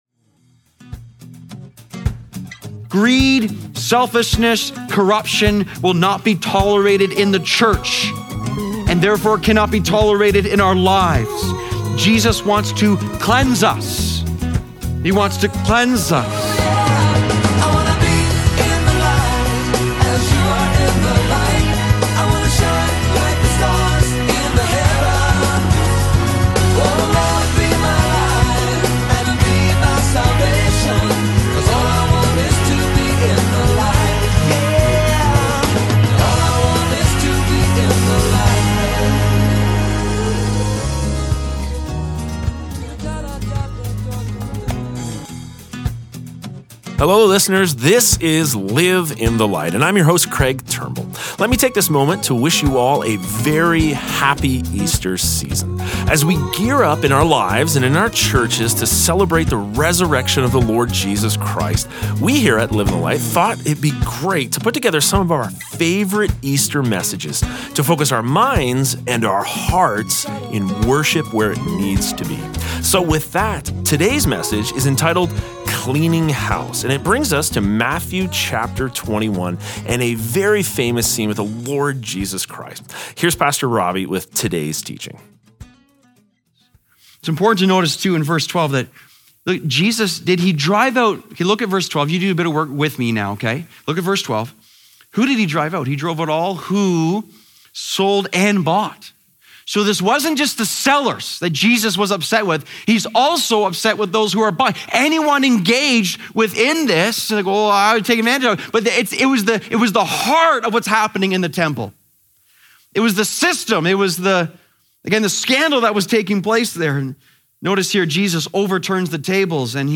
Today is Part 2 of the message Cleaning House on Live in the Light Easter.